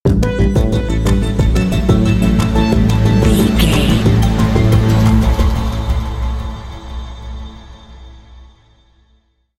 Aeolian/Minor
synthesiser
drum machine
ominous
dark
suspense
haunting
creepy